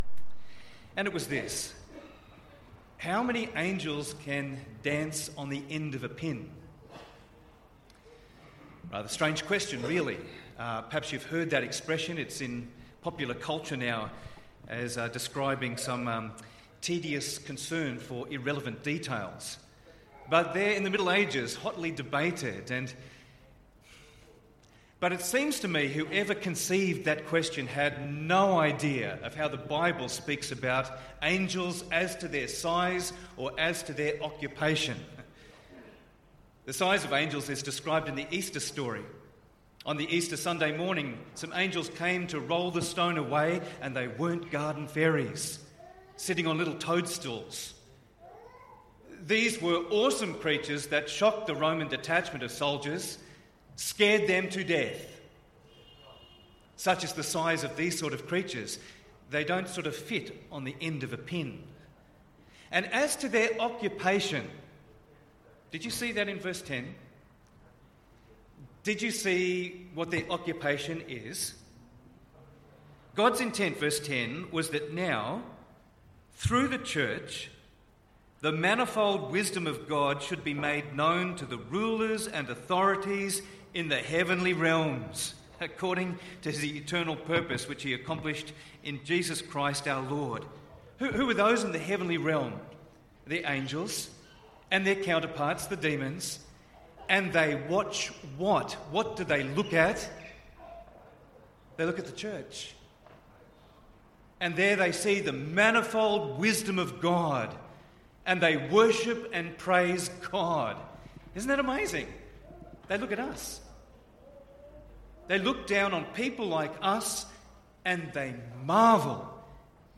Ephesians 3:1-21 - Hurstville Presbyterian Church